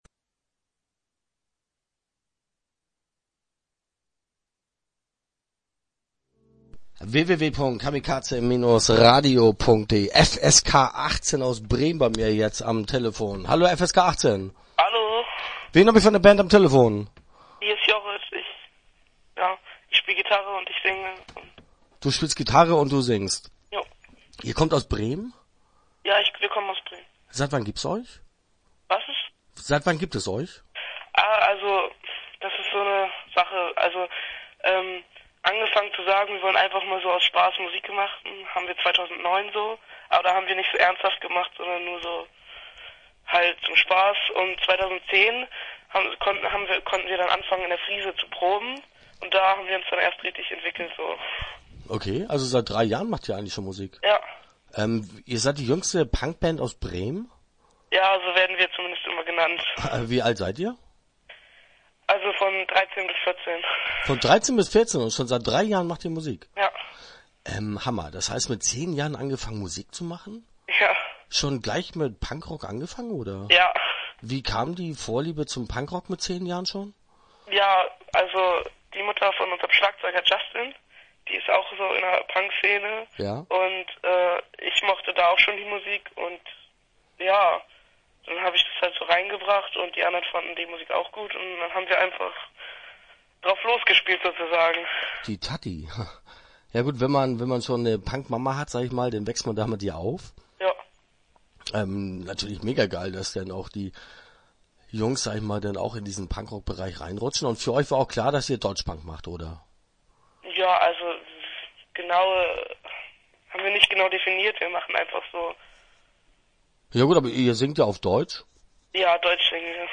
Start » Interviews » FSK18